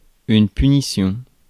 Ääntäminen
IPA: [py.ni.sjɔ̃]